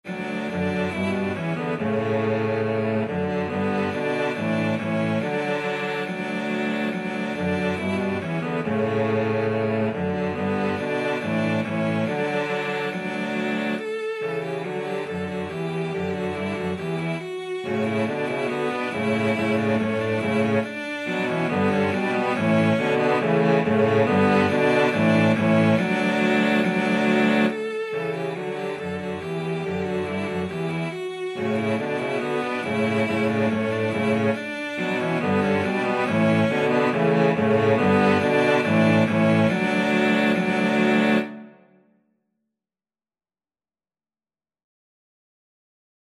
2/2 (View more 2/2 Music)
Cello Trio  (View more Easy Cello Trio Music)